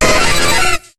Cri d'Électhor dans Pokémon HOME.